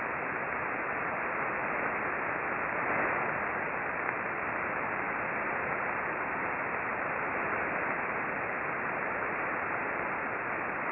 RFI with a popping sound is apparent in the recordings.
The next detected burst occurred just before 1052, again on the lower frequency.
Click here for a mono recording of the strong burst followed by weaker bursts.